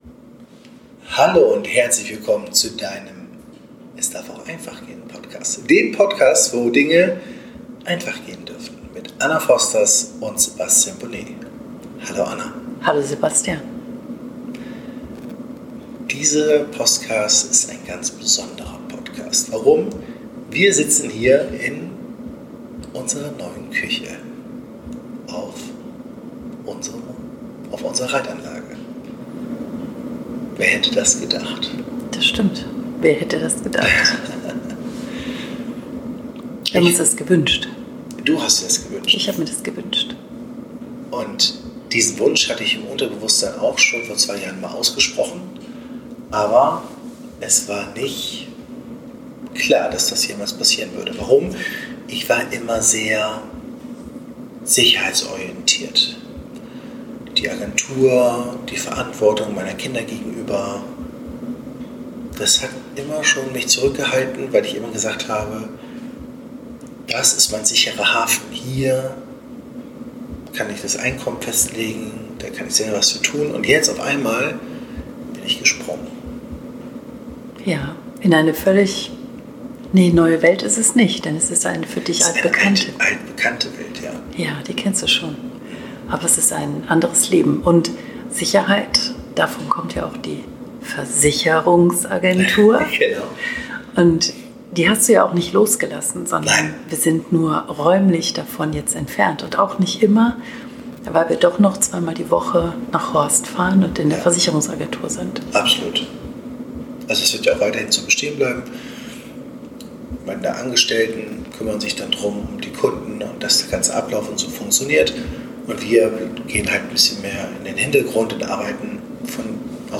Ein inspirierendes Gespräch über persönliche Transformation und die Kraft der Pferde.